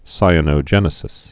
(sīə-nō-jĕnĭ-sĭs, sī-ănō-)